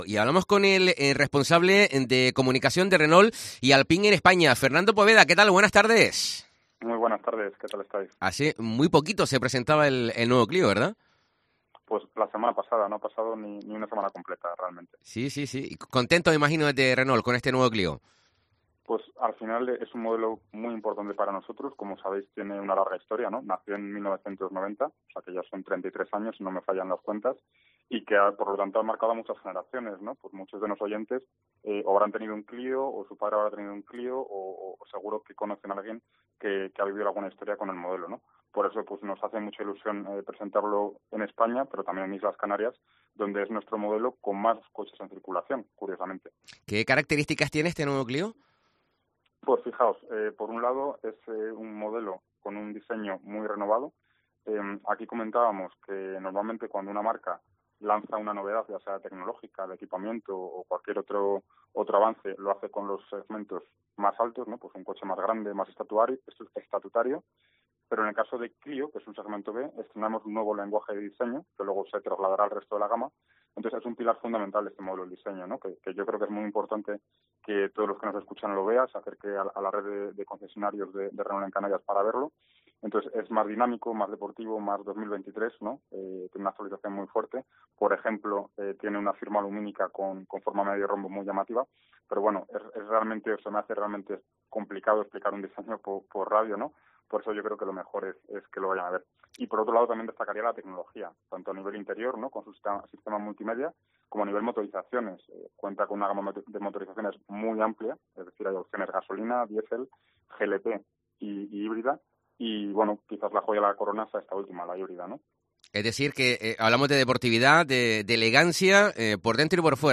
Deportes Gran Canaria Entrevista